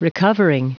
Prononciation du mot recovering en anglais (fichier audio)